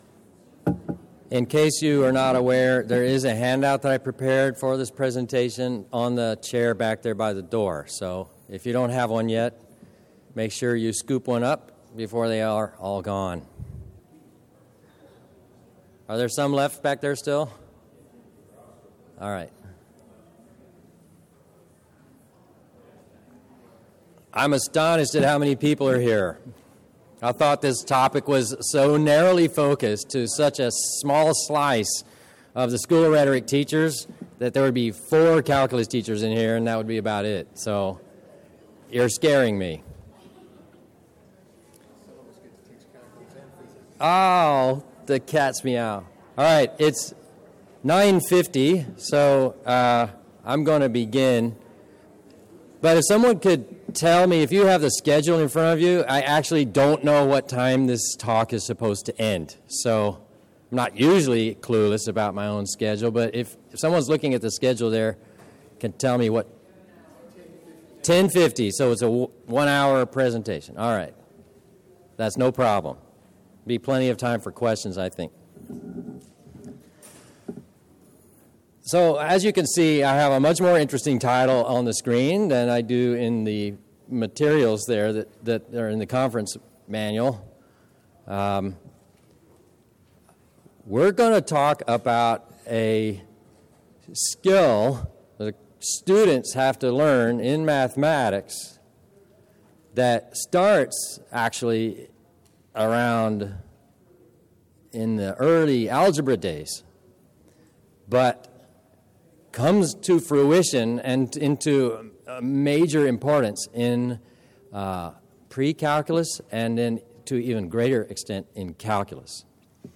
2012 Workshop Talk | 1:03:53 | Leadership & Strategic